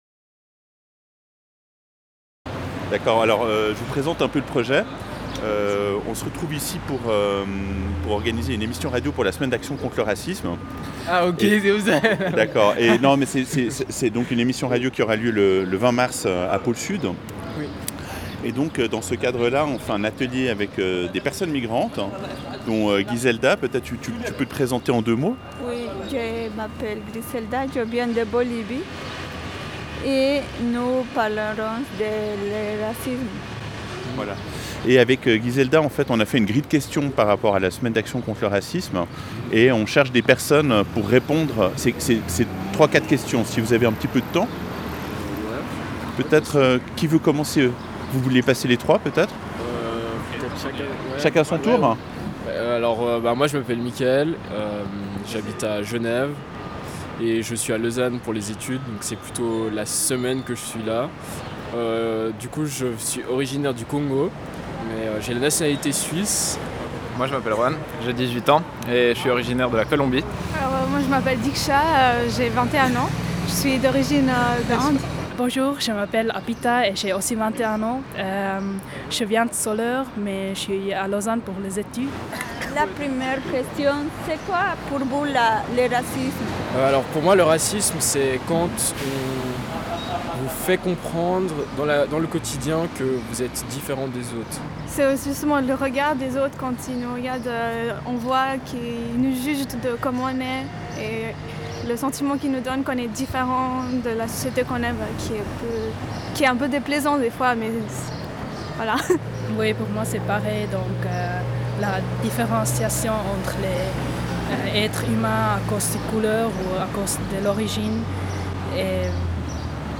Interviews de la population lausannoise
Voici une première série d’interviews menées le 23 janvier dans le quartier lausannois du Flon à Lausanne.